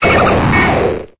Cri de Qulbutoké dans Pokémon Épée et Bouclier.